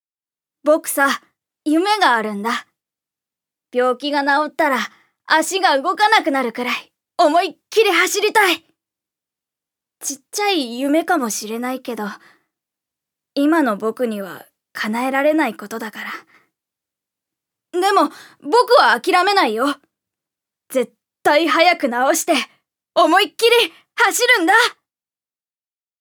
預かり：女性
セリフ５